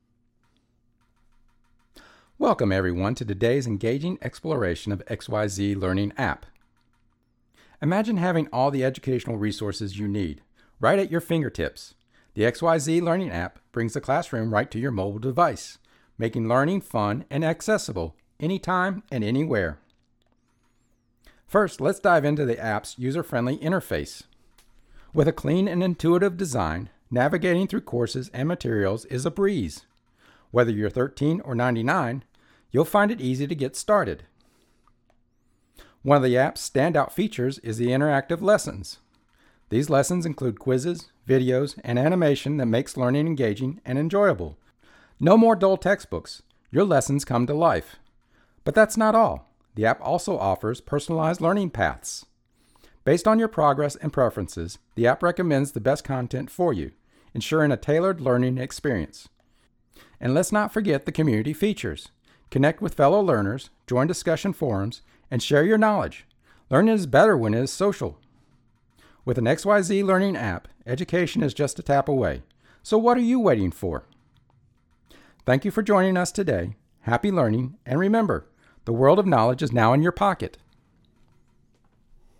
English Speaking (US), neutral and southern dialects, young adult to mature senior voice
Sprechprobe: eLearning (Muttersprache):
Rode NT1 condenser mic Rode Pop screen Zoom H1 XLR Recorder Focusrite Scarlett 2i12 (3rd gen) interface Audacity DAW